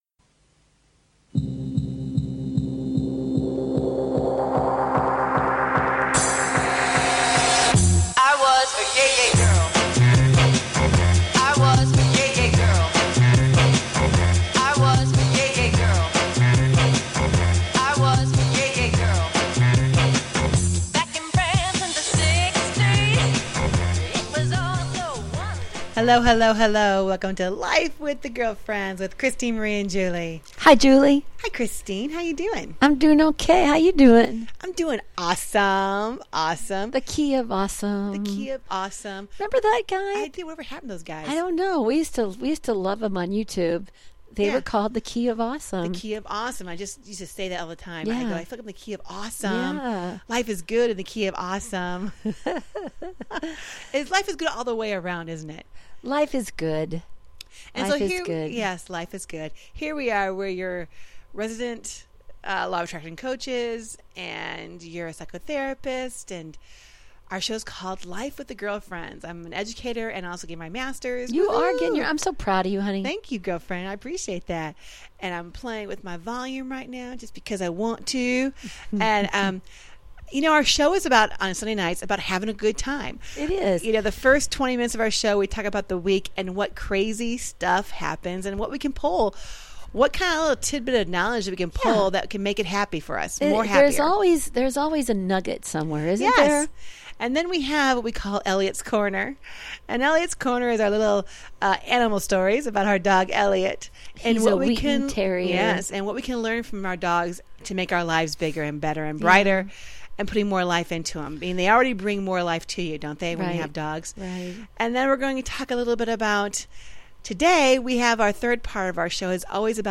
Talk Show Episode, Audio Podcast
And join the girlfriends up close and personal for some daily chat that’s humorous, wholesome, and heartfelt.